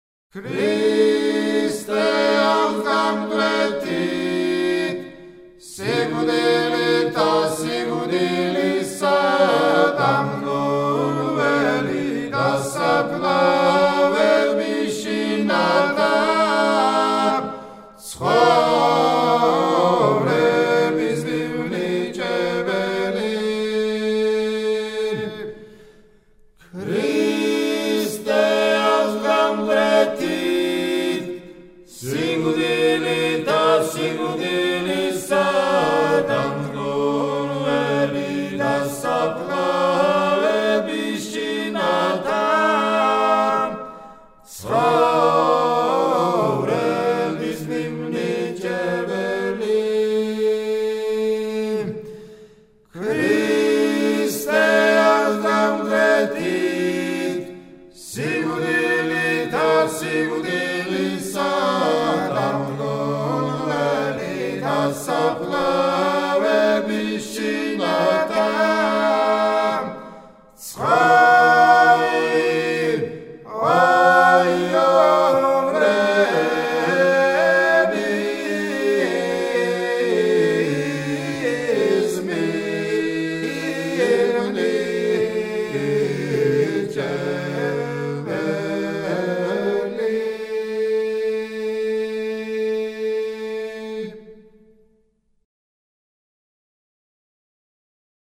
საგალობელი